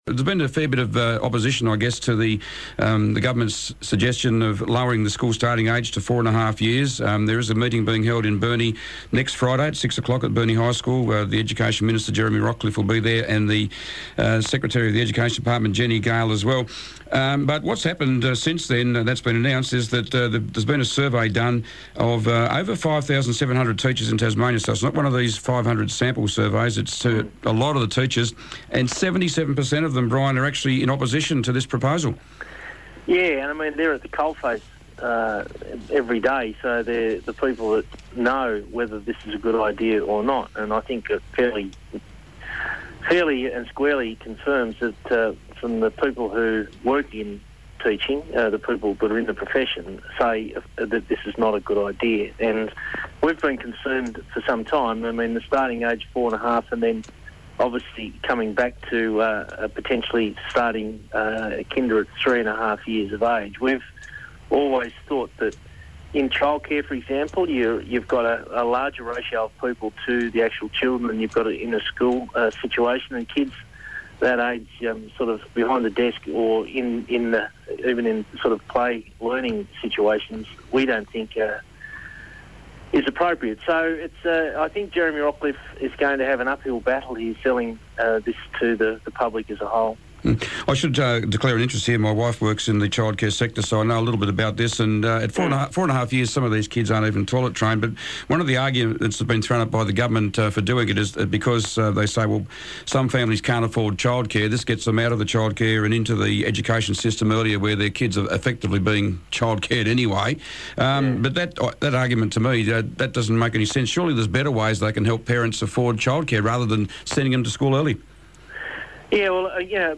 Tasmanian Opposition Leader Bryan Green hopped up onto the Pollies Perch today. Bryan spoke about proposed changes to the school starting age, the need for an independent inquiry into cloud seeding during the recent floods, and the refusal of the treasurer and energy minister to appear before a committee inquiring how the recent Tasmanian energy crisis was handled . . .